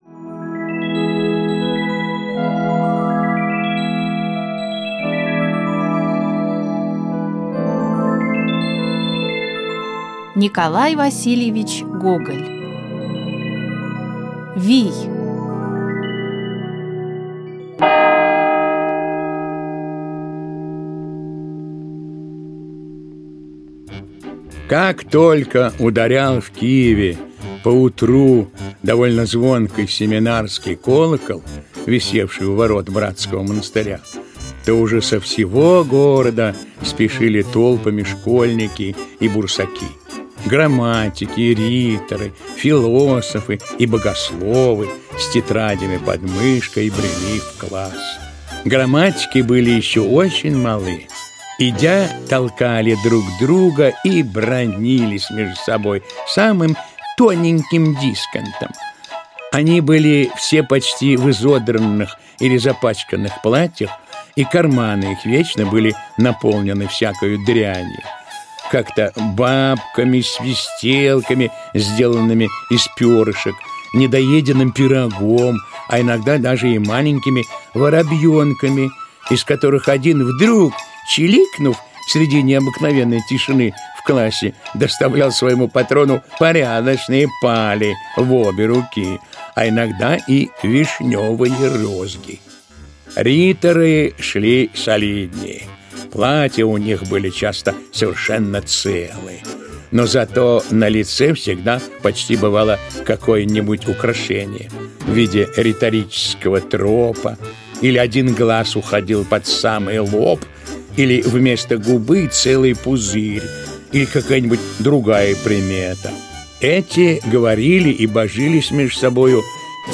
Вий - аудио повесть Гоголя - слушать повесть онлайн